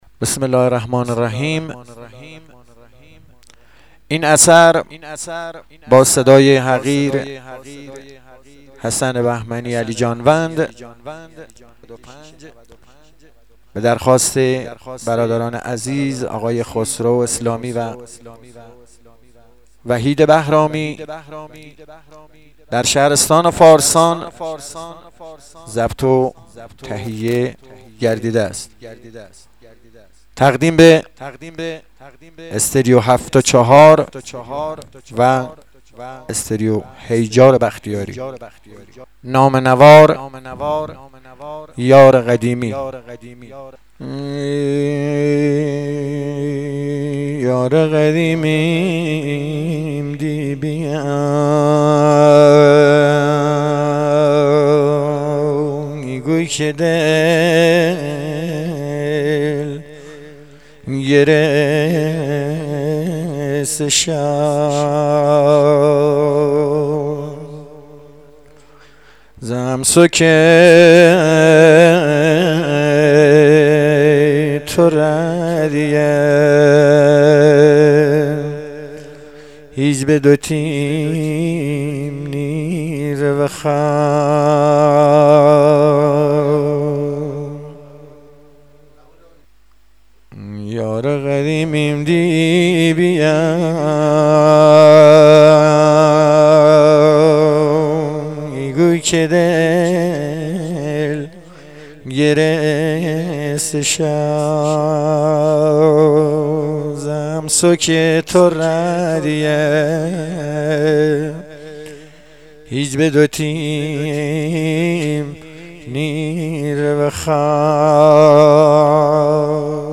(غمگین)